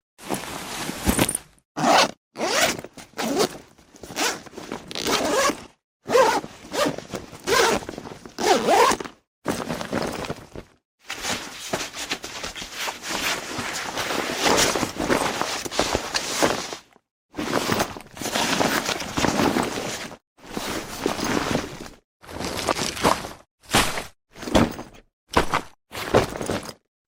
Tổng hợp tiếng Balo, túi Xách… Xột xoạt, Lách cách, sửa soạn [Phần 2]
Thể loại: Tiếng đồ vật
Description: Tổng hợp hiệu ứng âm thanh chi tiết của balo, túi xách khi sửa soạn, di chuyển – bao gồm các tiếng xột xoạt, lách cách, sột soạt, lạch cạch, lục cục, và lọc xọc, tiếng kéo dây kéo, tiếng va chạm nhẹ của khóa kéo, quai đeo, và vật dụng bên trong va vào nhau.
tong-hop-tieng-balo-tui-xach-xot-xoat-lach-cach-sua-soan-phan-2-www_tiengdong_com.mp3